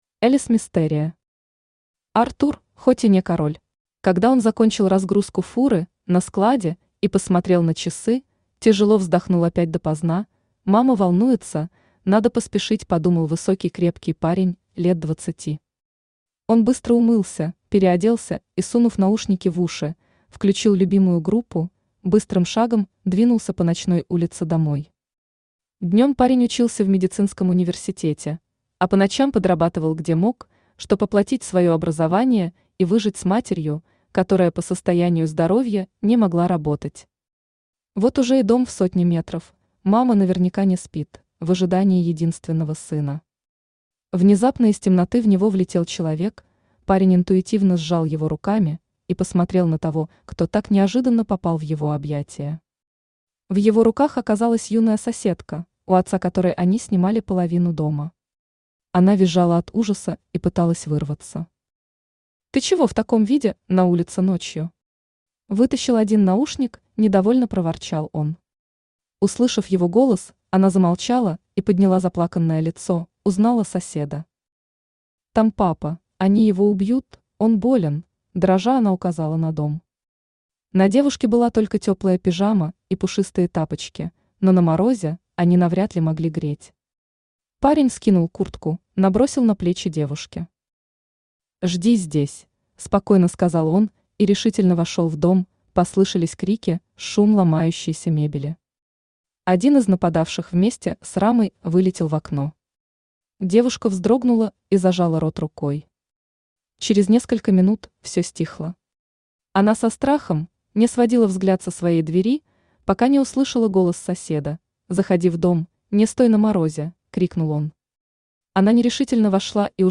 Аудиокнига Артур, хоть и не король | Библиотека аудиокниг
Aудиокнига Артур, хоть и не король Автор Элис Мистерия Читает аудиокнигу Авточтец ЛитРес.